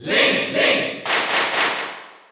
Category: Crowd cheers (SSBU)
Link_&_Toon_Link_Cheer_Dutch_SSBU.ogg